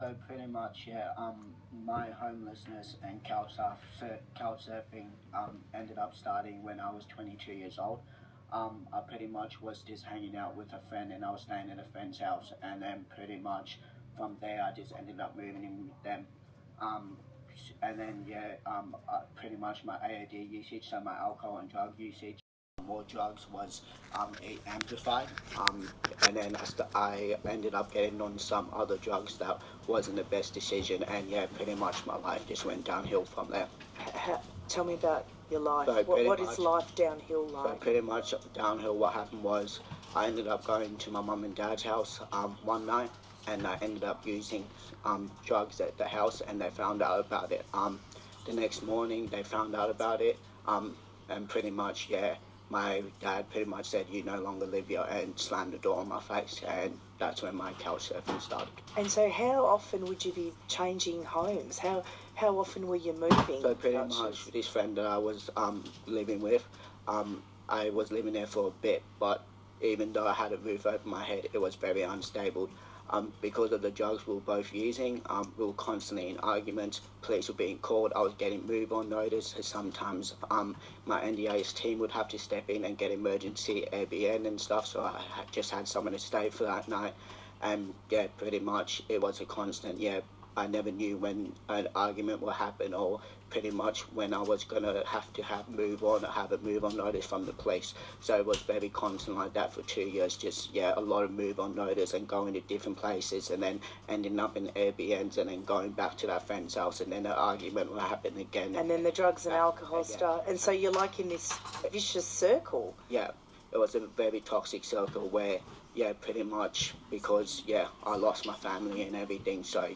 ABC-Interview.m4a